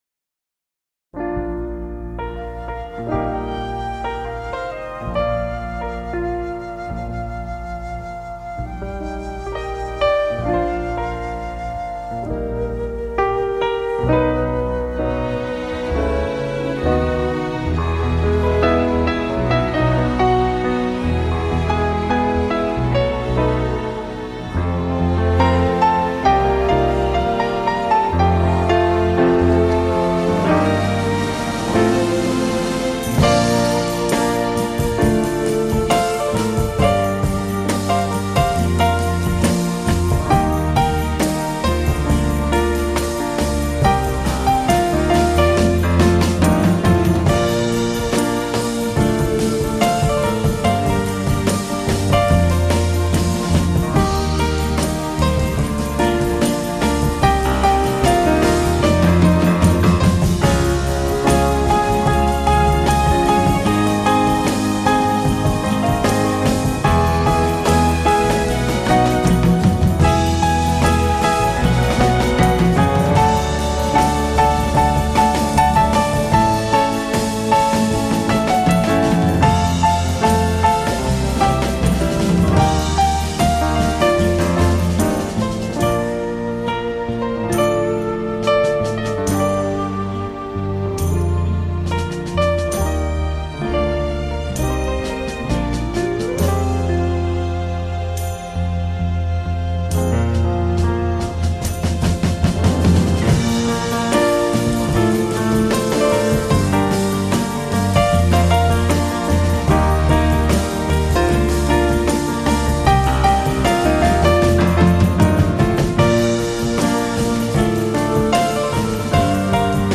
fait claquer ses cuivres, roucouler ses cordes
des moments de tension et des passages plus mélancoliques